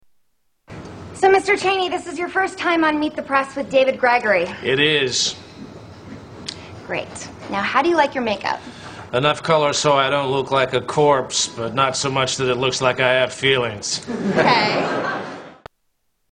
Tags: Comedians Darrell Hammond Darrell Hammond Impressions SNL Television